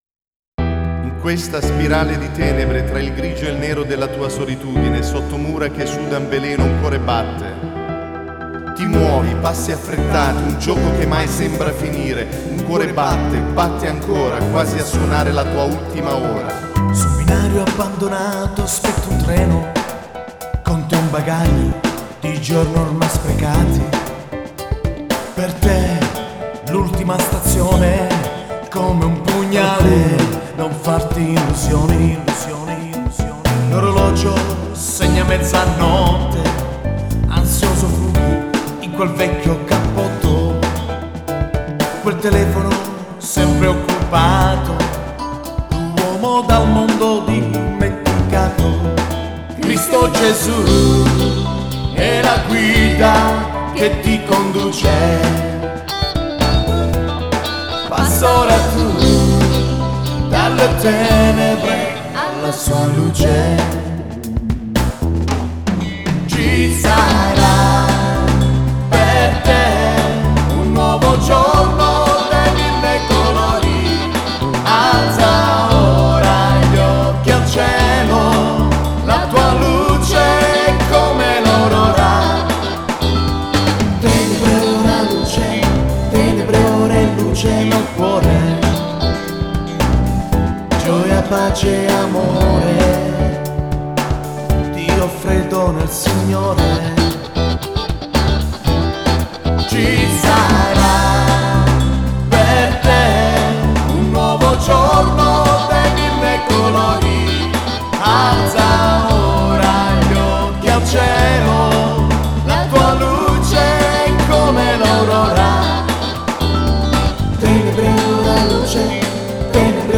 Pop Gospel Song- Dalle Tenebre alla luce- von der Finsternis zum Licht